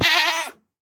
sounds / mob / goat / hurt4.ogg
hurt4.ogg